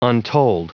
Prononciation du mot untold en anglais (fichier audio)
Prononciation du mot : untold